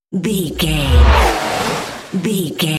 Whoosh electronic metal
Sound Effects
bouncy
bright
futuristic
intense
whoosh